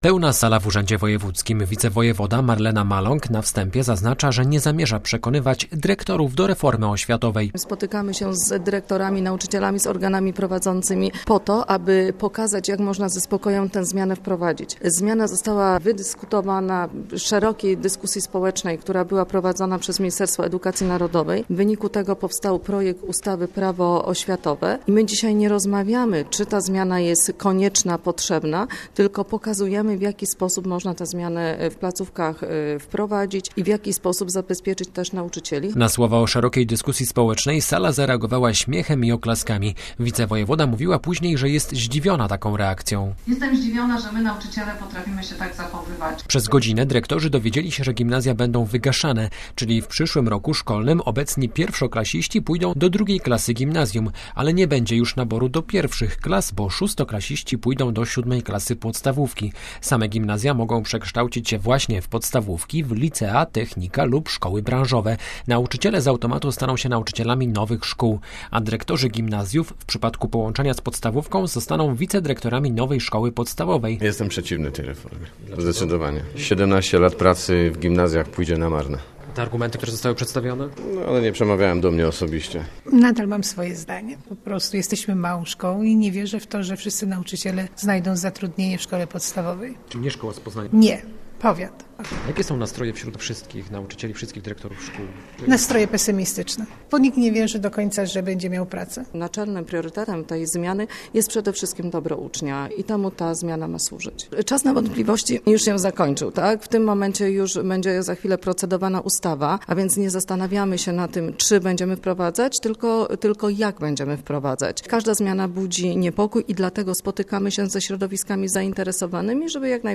Dziś w Urzędzie Wojewódzkim w Poznaniu odbyła się debata oświatowa.
Słowa wicewojewody sala przyjęła śmiechem połączonym z oklaskami.
75jmwzxk2jq67my_debata_o_szkolach.mp3